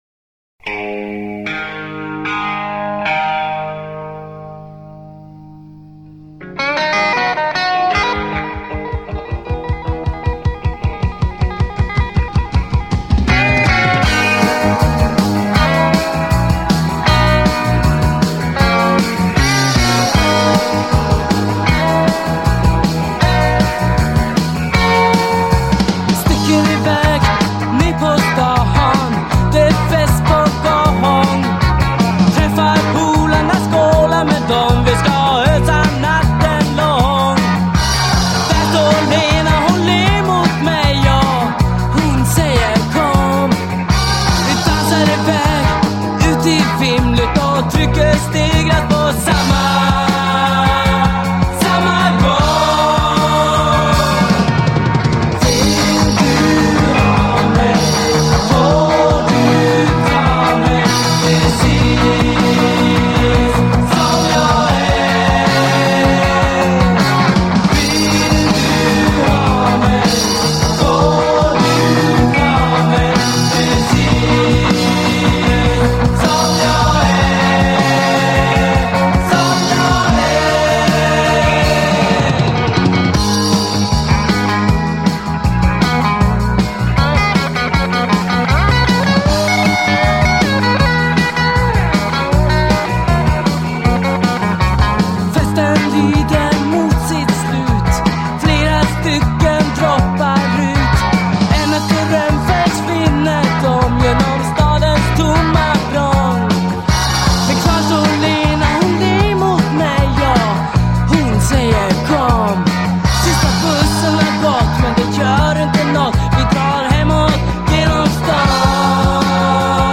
Här bjuder vi på tidlös svensk-pop av klassiskt snitt.